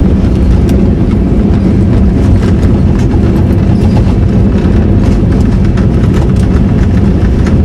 Add aft wing sounds
Roll.wav